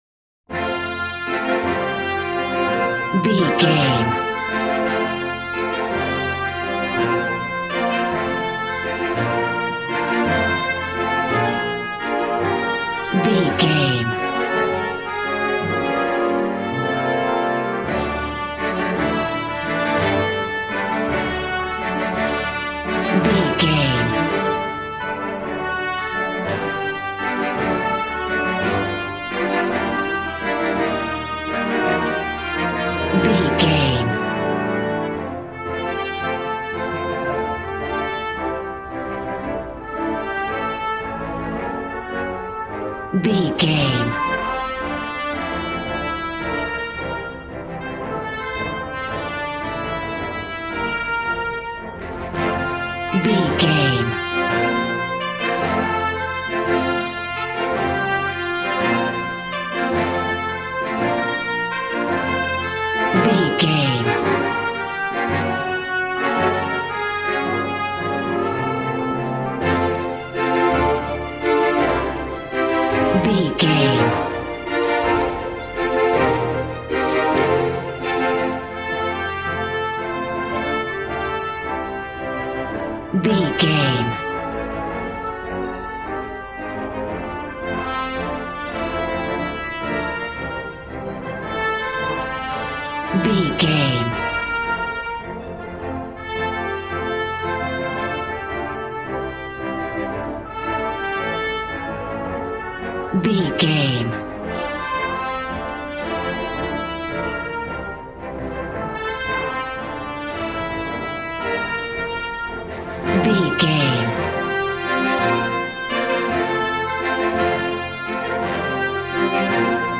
Ionian/Major
percussion
violin
cello